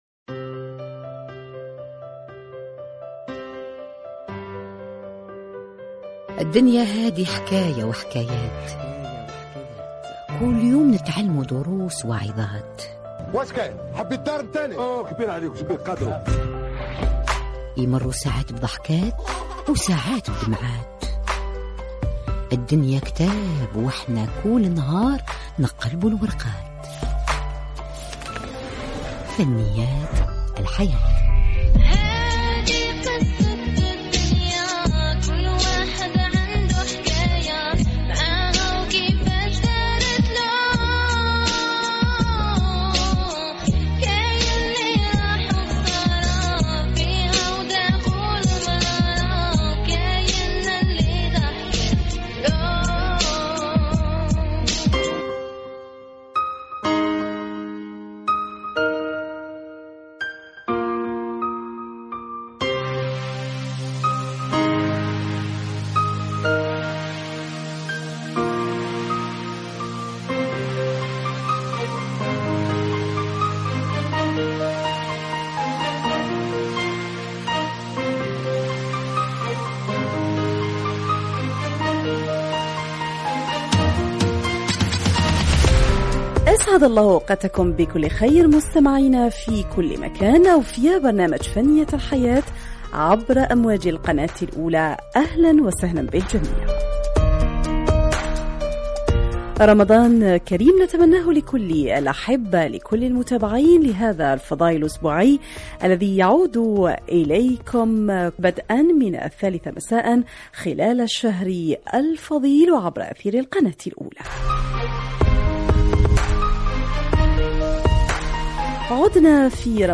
لقاء على أثير الإذاعة الجزائرية - القناة الأولى - برنامج فنيات الحياة 22/04/2020